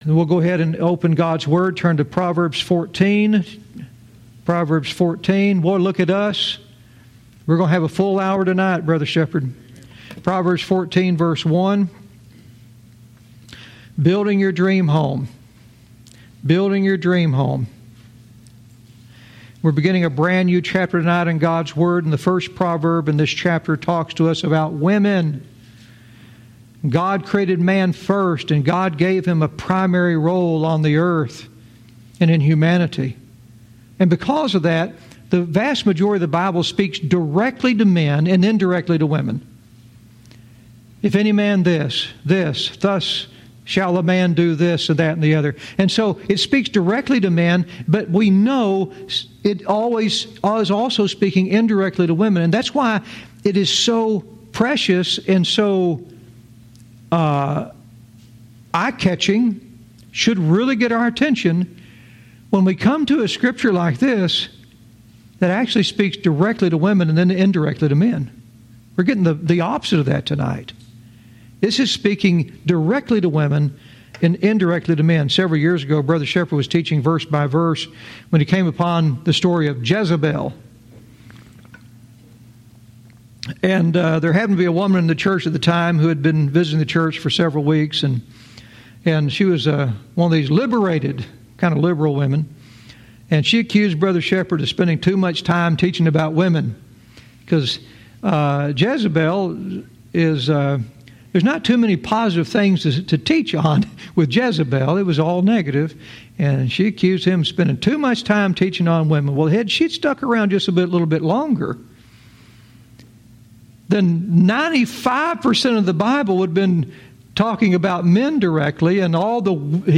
Verse by verse teaching - Proverbs 14:1 "Building your dream home"